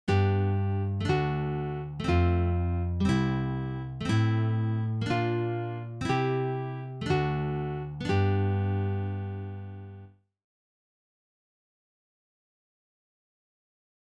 Here’s an example of a fragile progression, where the harmonic goal is a little less obvious:
FRAGILE: G  D  F  C  Am  Bb  C  D  G [
As you can see, the fragile progression does one thing that the strong progression does: it starts and ends on G. But the fragile one takes a little side-journey that makes C sound like the tonic for a short while, before making G the clear objective.